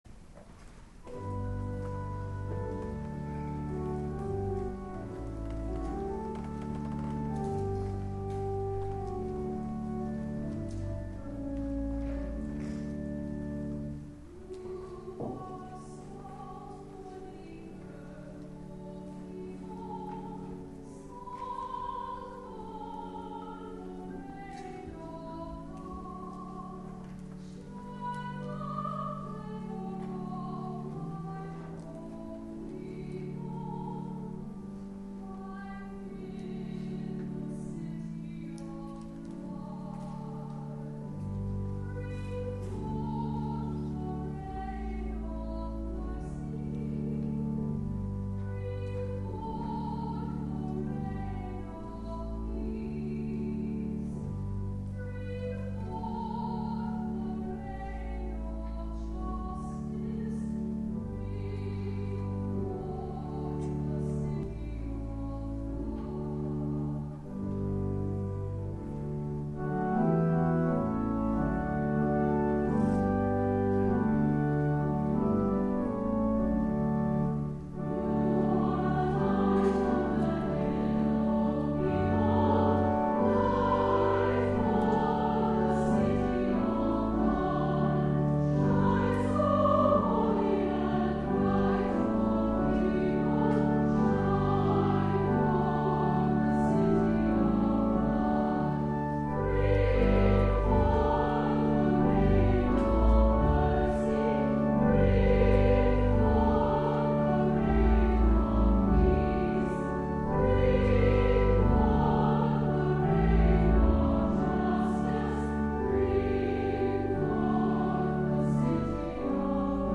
THE OFFERTORY
soloist